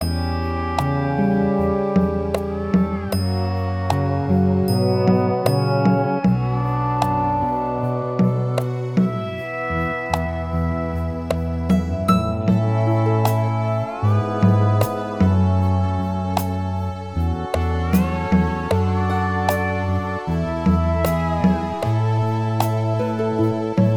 Minus Acoustic Guitar Soft Rock 4:30 Buy £1.50